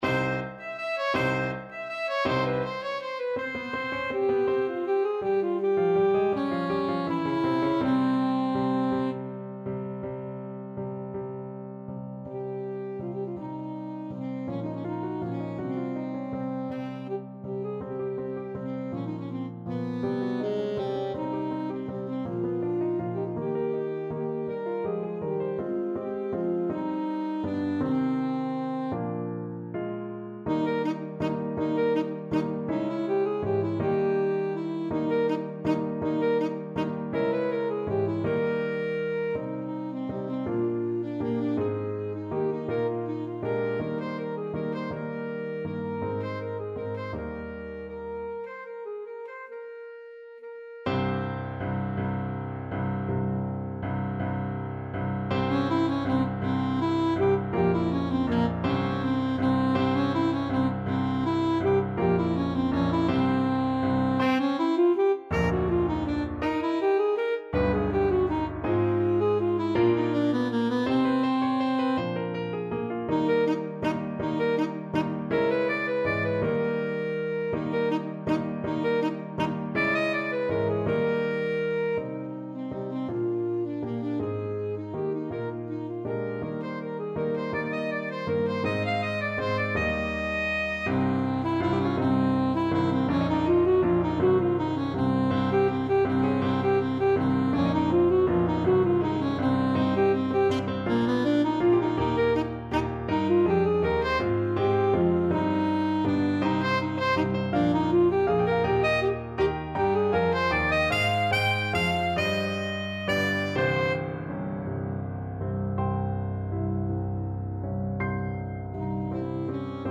Alto Saxophone
One in a bar . = c.54
3/4 (View more 3/4 Music)
Christmas (View more Christmas Saxophone Music)